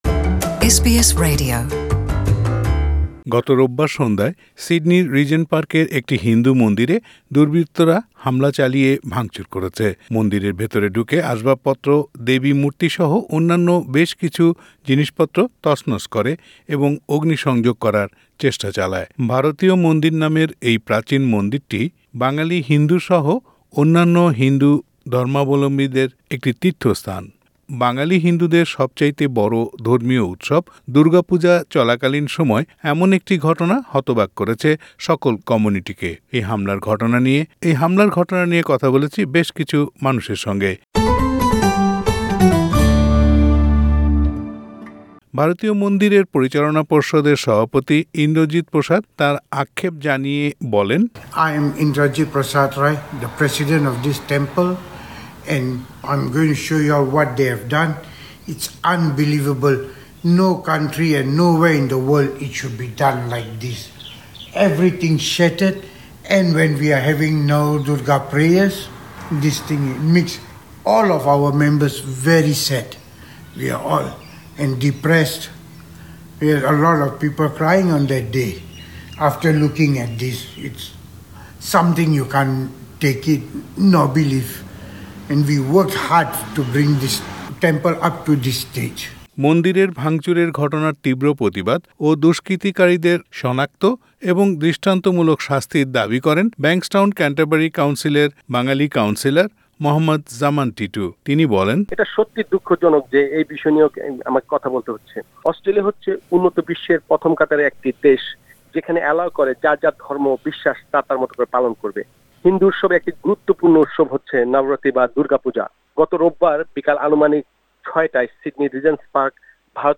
বাঙালি হিন্দুদের অন্যতম ধর্মীয় উৎসব দুর্গা পূজা চলাকালে এমন একটি ঘটনা হতবাক করেছে বাংলাভাষী কমিউনিটিকে। কমিউনিটির সদস্যদের প্রতিক্রিয়া নিয়ে একটি প্রতিবেদন।